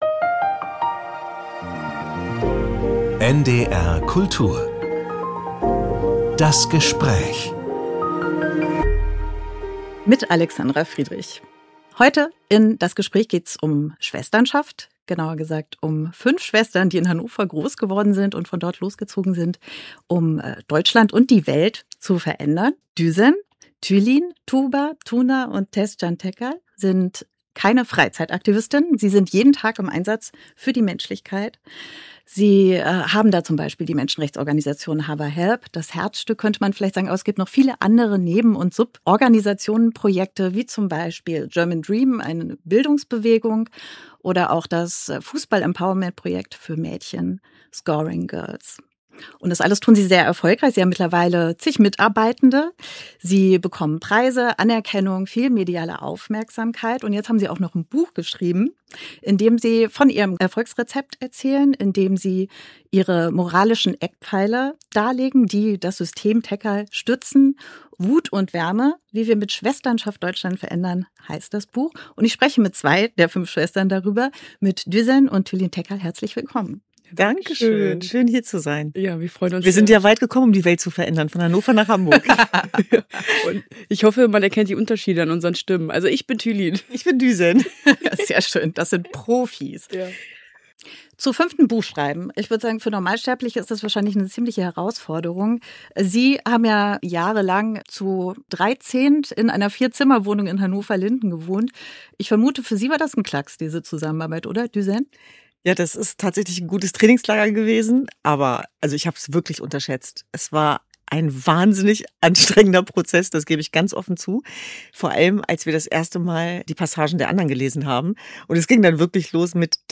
NDR Kultur - Das Gespräch Podcast